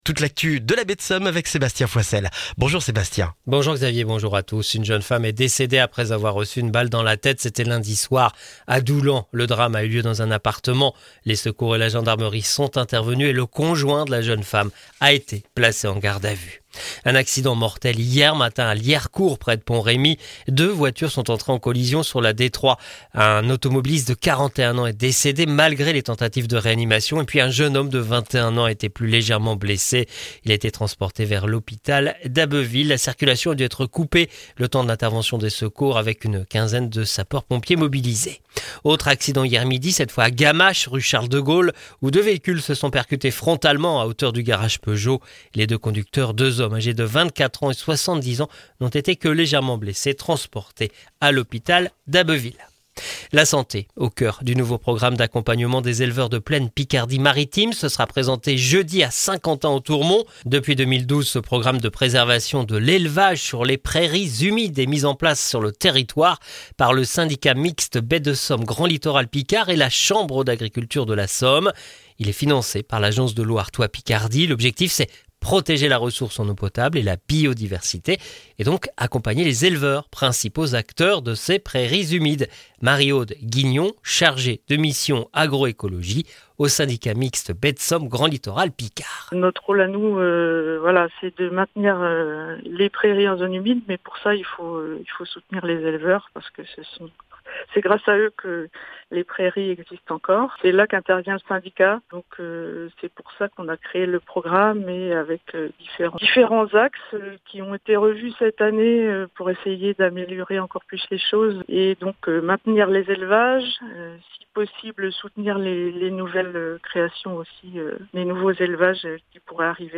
Le journal du mercredi 6 novembre en Baie de Somme et dans la région d'Abbeville